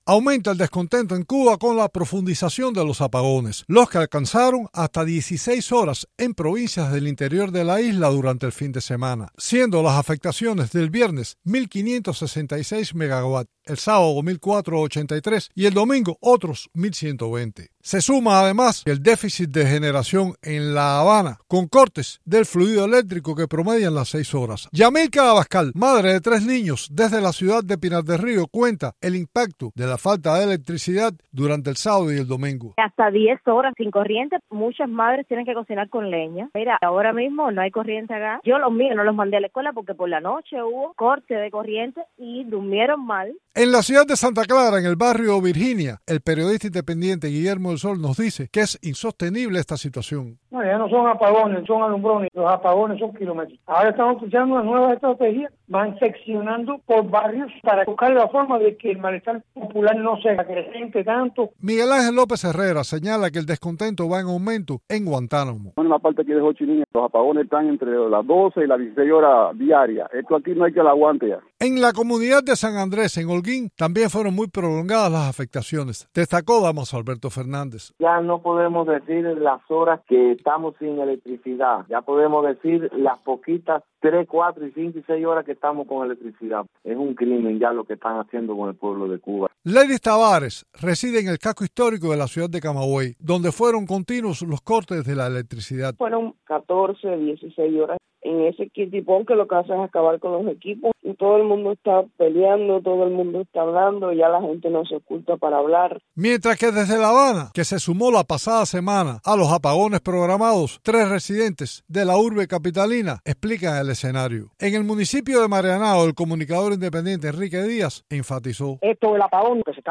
Residentes en varias provincias cubanas describen el malestar por los intensos apagones